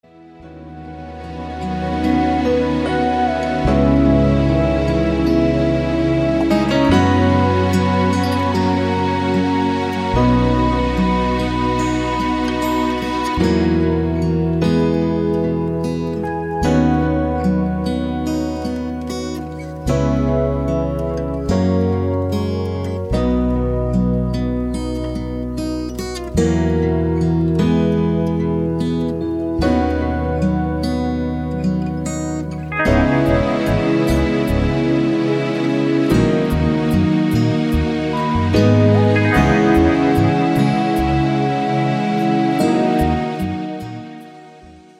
Midi Demo